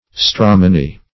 stramony - definition of stramony - synonyms, pronunciation, spelling from Free Dictionary Search Result for " stramony" : The Collaborative International Dictionary of English v.0.48: Stramony \Stram"o*ny\, n. (Bot.)